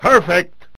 Vox (Pt 2).wav